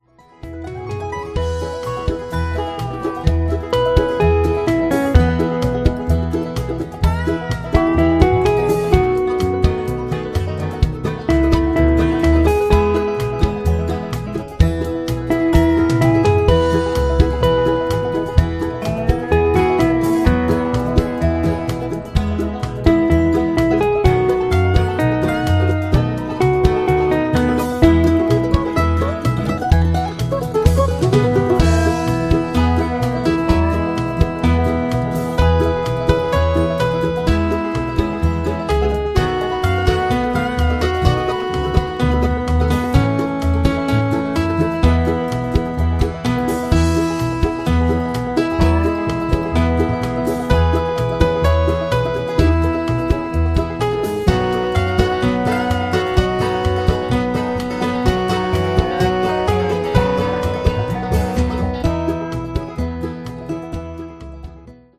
(with Melody)
Singing Calls